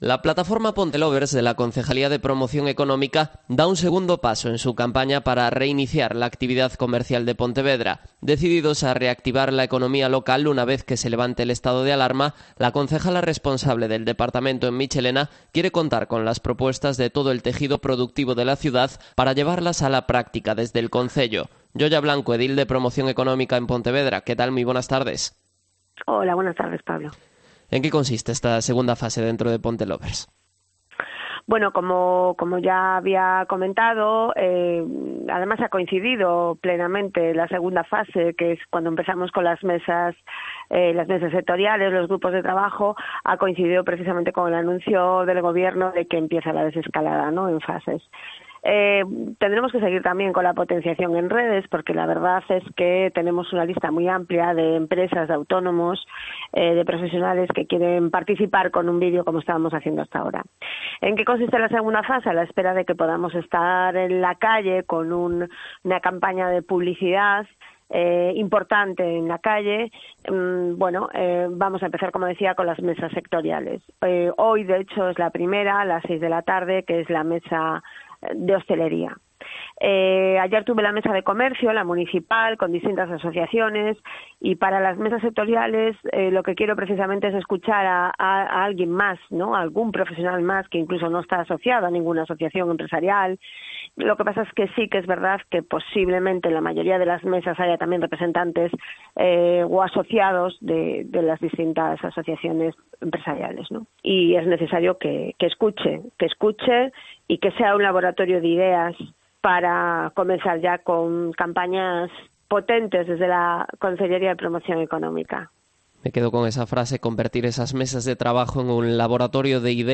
Entrevista a Yoya Blanco, edil de Promoción Económica de Pontevedra